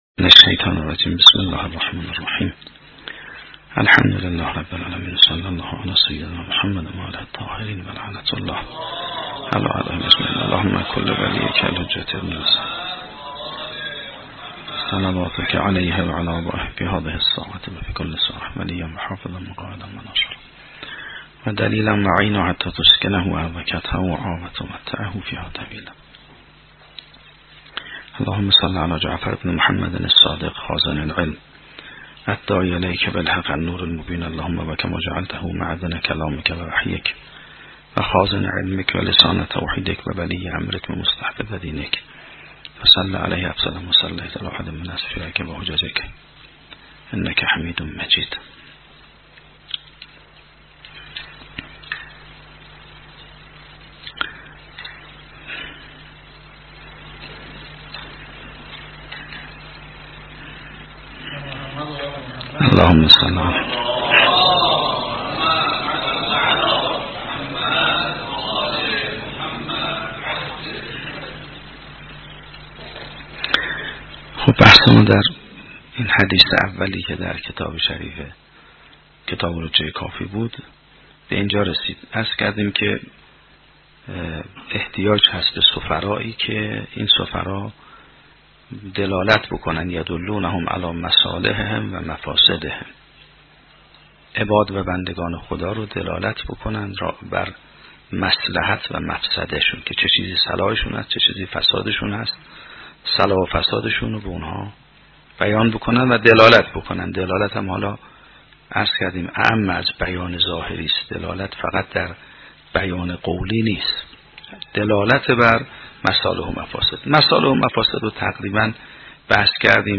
شرح و بررسی کتاب الحجه کافی توسط آیت الله سید محمدمهدی میرباقری به همراه متن سخنرانی ؛ این بخش : حقیقت فناء و بقاء در حیات دنیا و آخرت و نیازمندی انسان به دلالت انبیاء